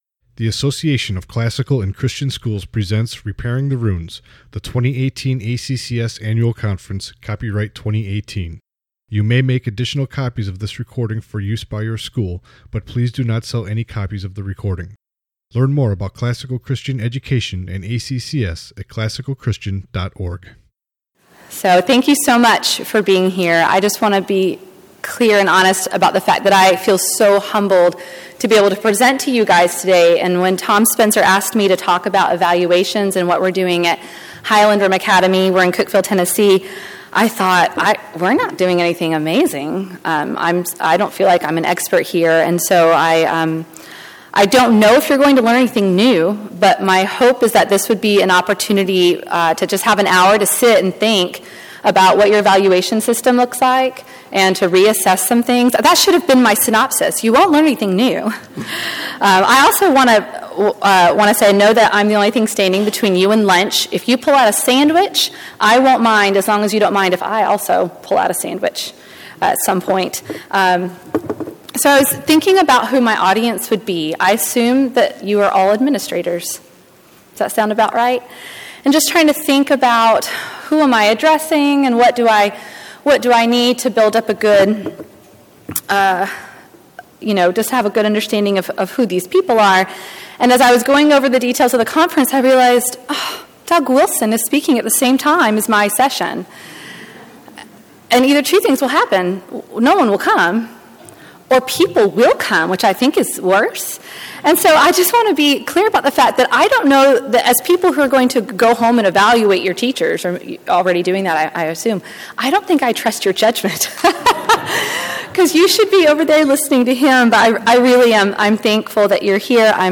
2018 Workshop Talk | 59:57 | All Grade Levels, Leadership & Strategic, Training & Certification